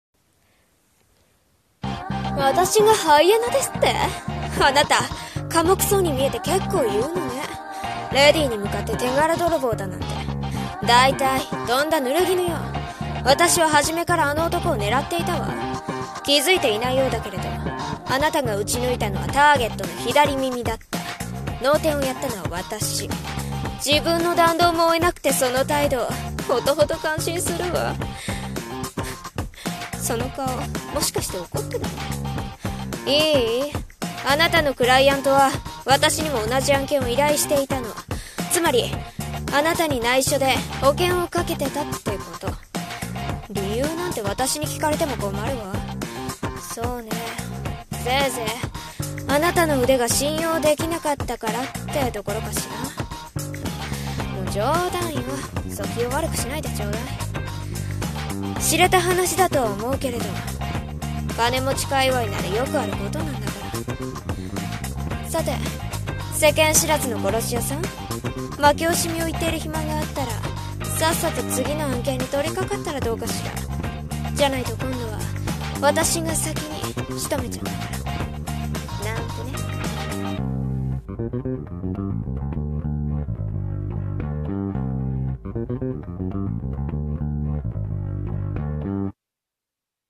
Lady【一人声劇】